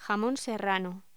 Locución: Jamón serrano
voz